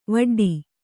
♪ vaḍḍi